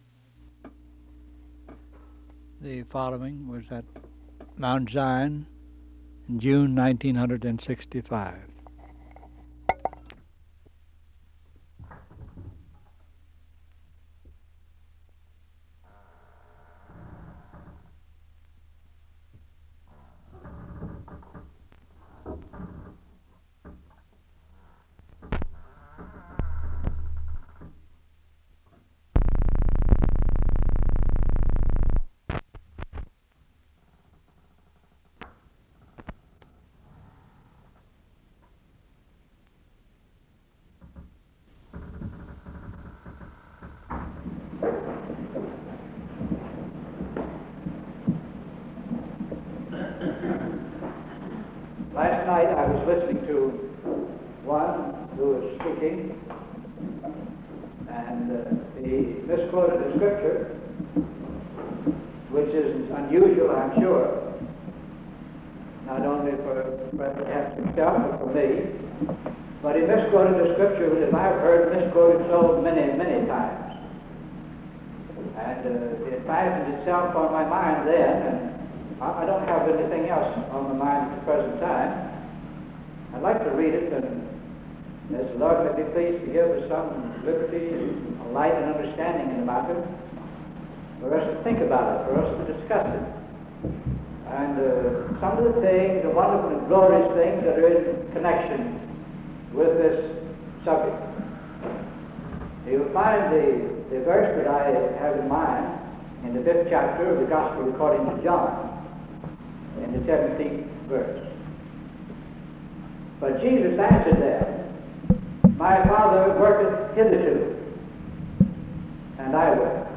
Location Aldie (Va.) Loudoun County (Va.)
sermon